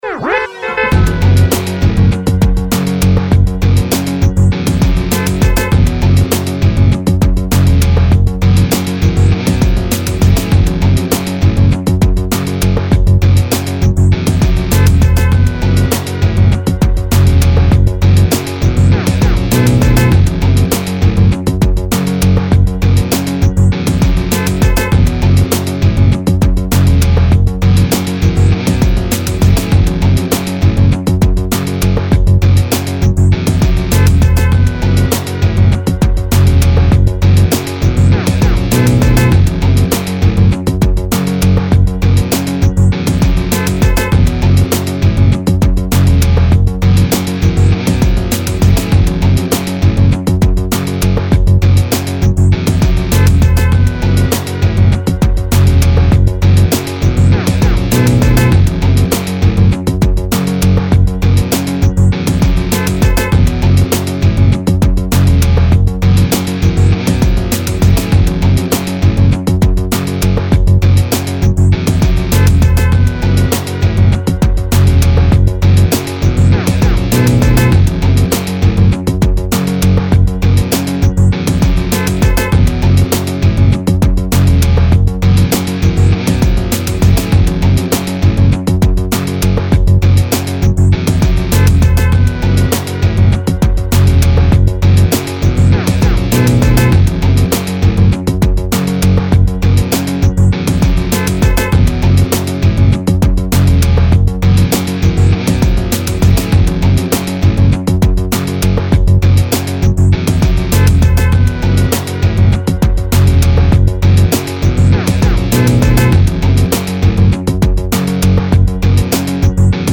radio traffic beds